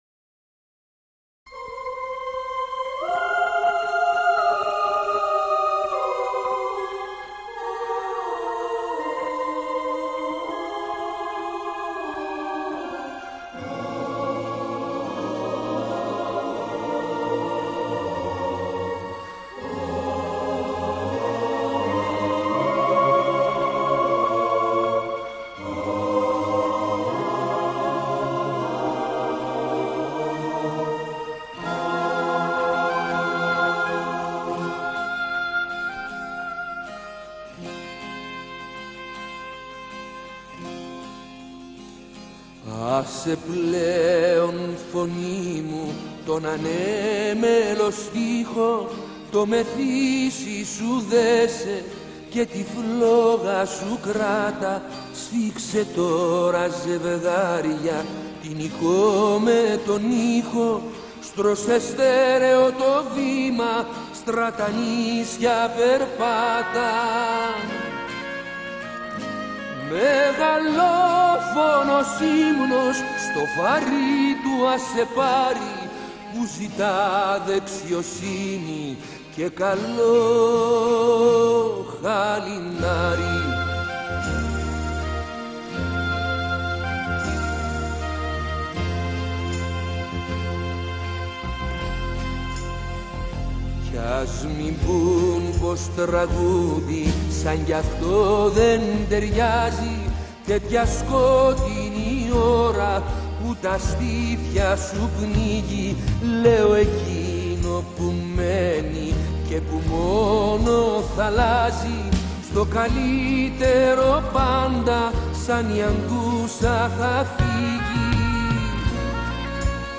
ΕΓΙΝΕ ΜΕ ΕΠΙΤΥΧΙΑ Η ΚΕΝΤΡΙΚΗ ΑΝΤΙΚΑΤΟΧΙΚΗ ΕΠΕΤΕΙΑΚΗ ΕΚΔΗΛΩΣΗ – ΖΩΓΡΑΦΟΥ – 17 ΙΟΥΛΙΟΥ 2025